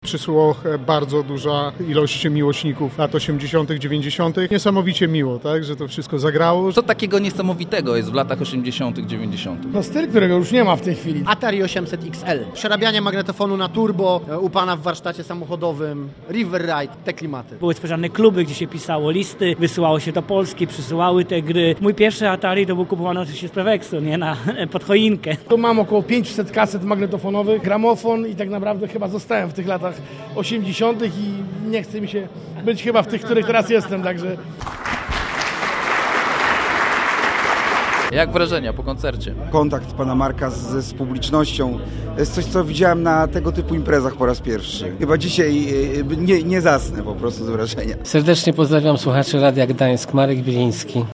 Posłuchaj skróconej relacji z imprezy: